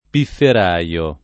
piffer#Lo] s. m.; pl. ‑rai — anche pifferaro [piffer#ro], spec. alludendo a regioni dove questa figura è tradizionale (e dov’è pure tradizionale in questa e in altre voci il suff. -aro) — cfr. Hameln